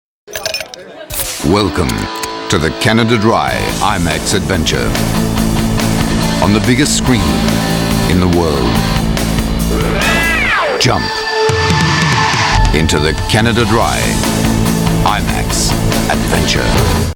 Well, I'd say it's deep, warm, persuasive, with sincerity and authority - but why not have a listen and judge for yourself?
Sprecher englisch / britisch.
Sprechprobe: Werbung (Muttersprache):